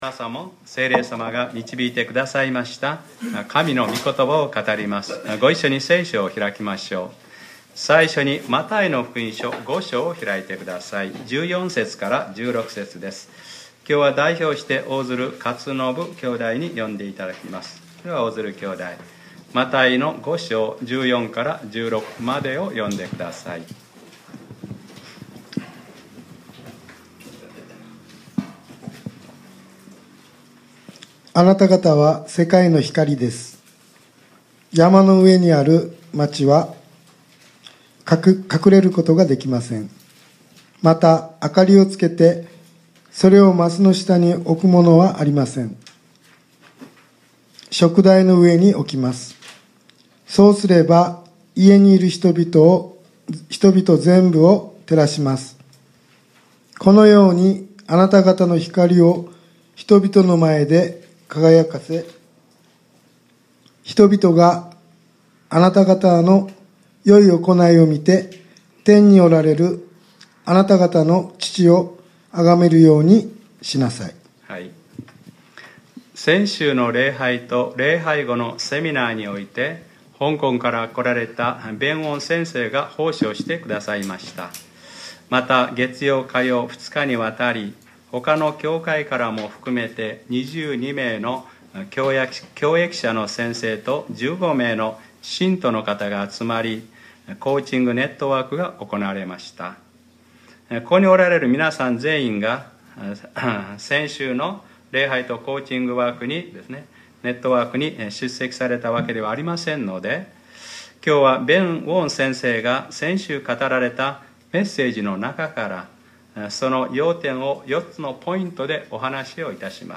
2015年5月17日（日）礼拝説教 『教会文化』